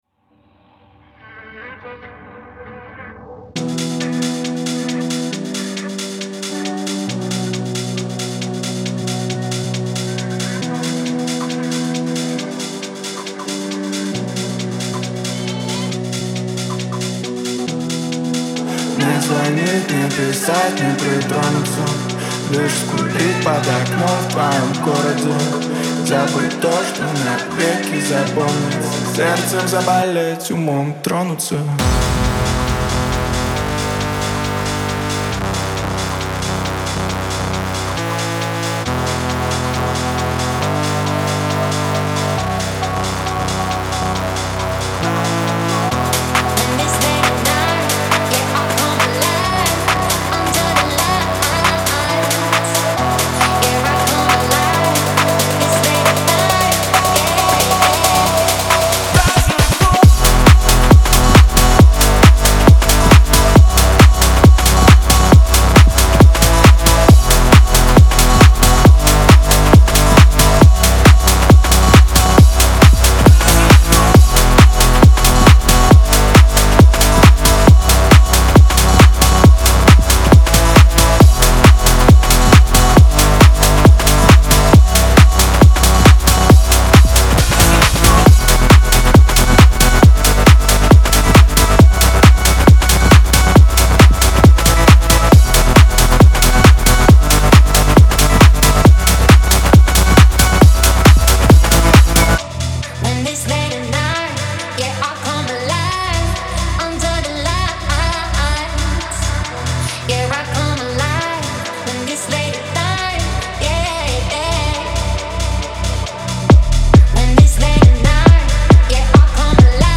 Трек размещён в разделе Русские песни / Электронная музыка.